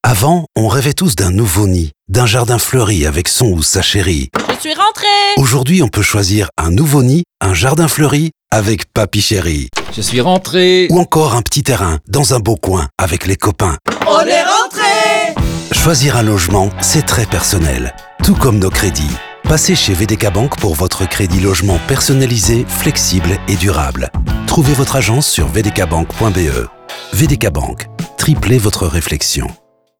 vdk Bank-Woonkrediet-radio-FR-30s.wav